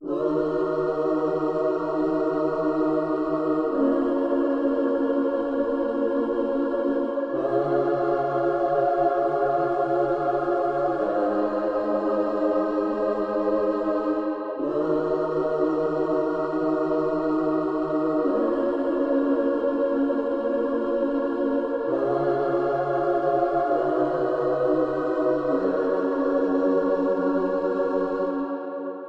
描述：8小节的女唱诗班在一个大教堂里唱歌。
Tag: 66 bpm Soul Loops Choir Loops 4.90 MB wav Key : F